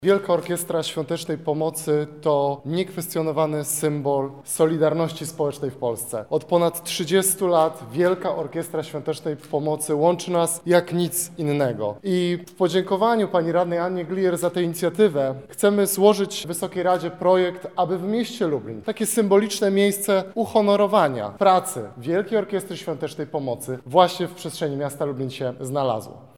– mówi Marcin Bubicz, Radny Miasta Lublin.